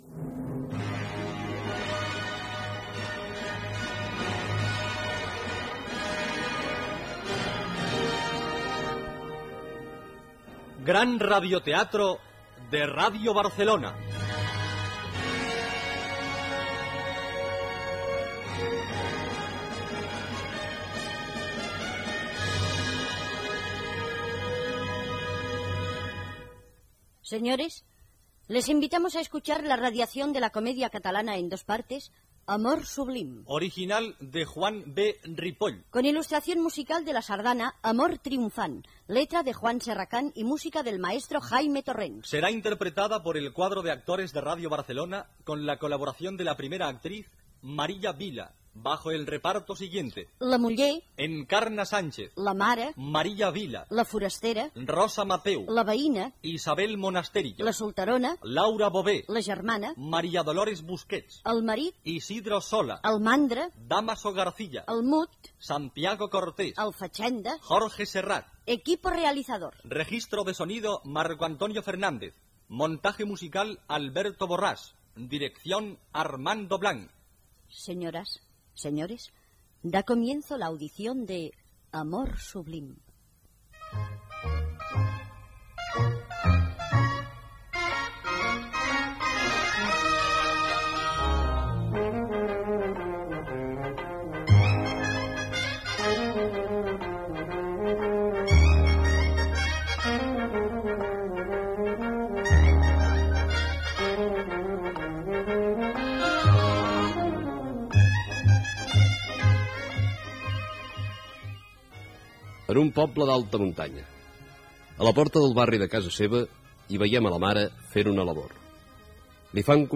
Fragment d'un diàleg dels protagonistes. Gènere radiofònic Ficció